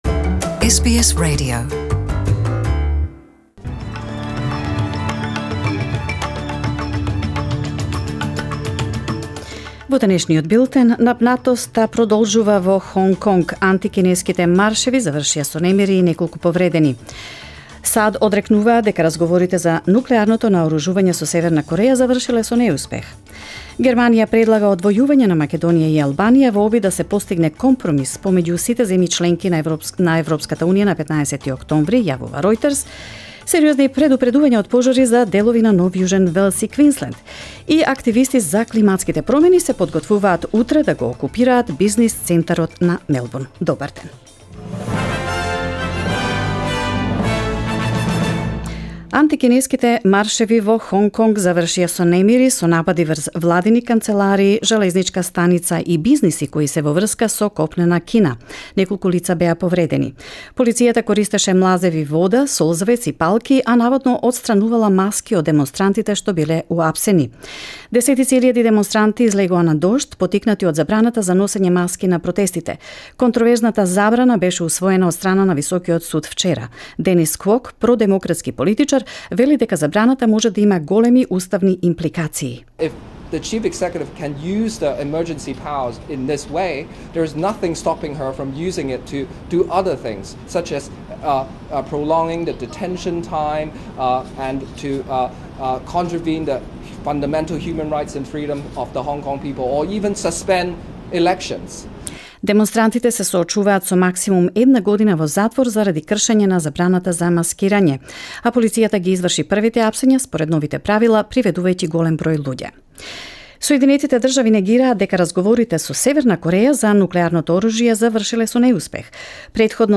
SBS News in Macedonian 7 October 2019